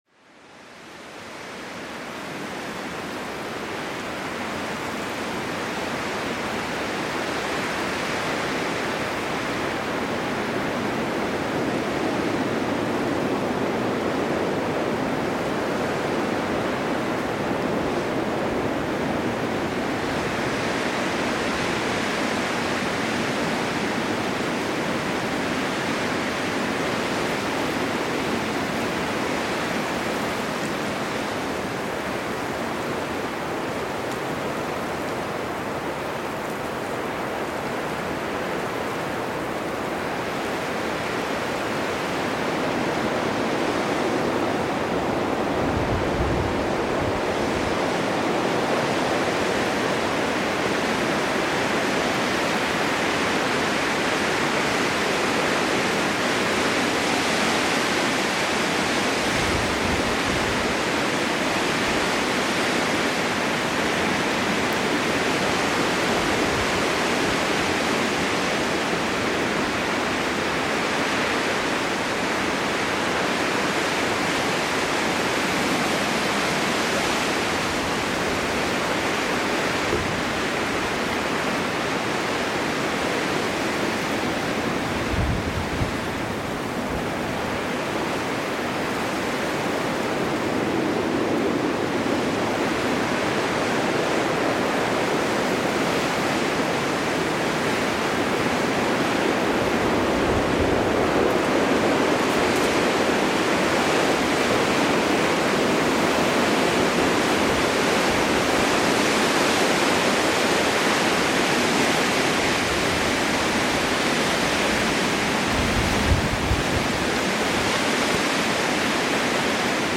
SORGEN-FLUCHT: Wintersturm-Meditation jagt Stress davon